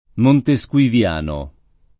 vai all'elenco alfabetico delle voci ingrandisci il carattere 100% rimpicciolisci il carattere stampa invia tramite posta elettronica codividi su Facebook montesquiviano [ monte S k U iv L# no ] (meno bene montesquieuiano [ monte S k L e L# no ; alla fr. monte S k L ö L# no ]) agg.